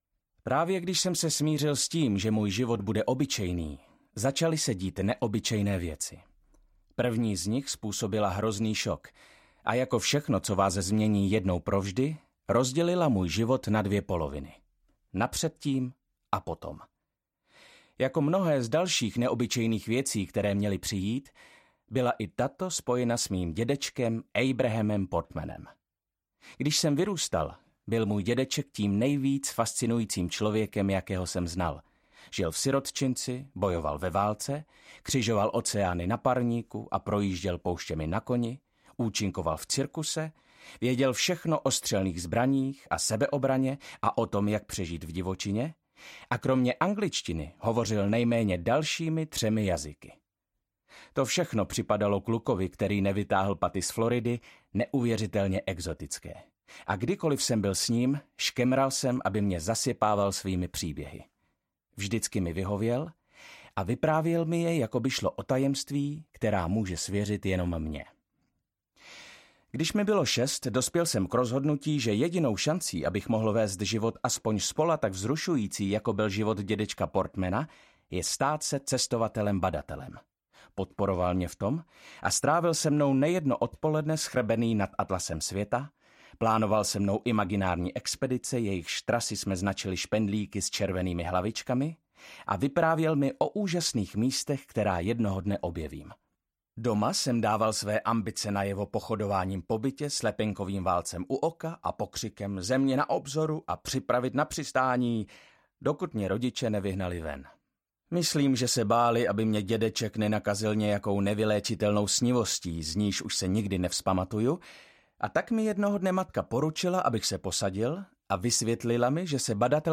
Ukázka z knihy
• InterpretViktor Dvořák